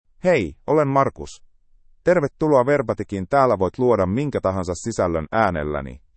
Marcus — Male Finnish AI voice
Marcus is a male AI voice for Finnish (Finland).
Voice sample
Listen to Marcus's male Finnish voice.
Marcus delivers clear pronunciation with authentic Finland Finnish intonation, making your content sound professionally produced.